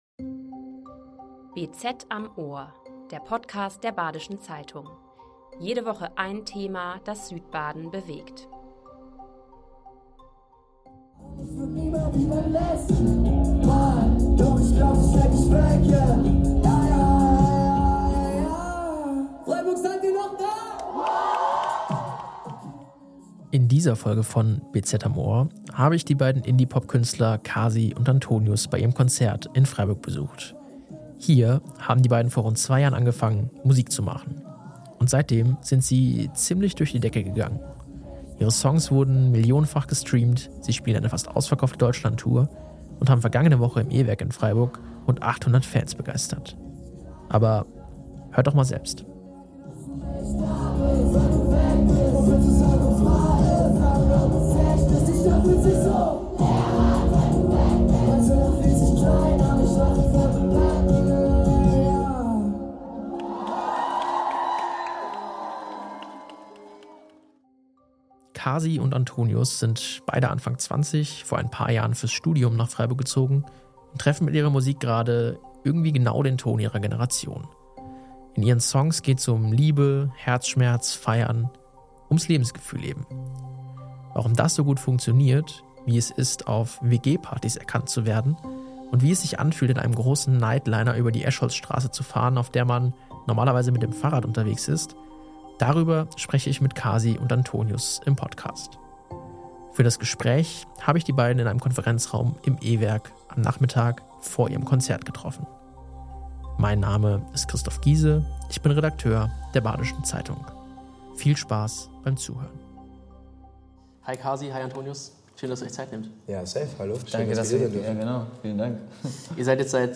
Das Gespräch fand in einem Konferenzraum im E-Werk am Nachmittag vor ihrem Konzert in Freiburg statt.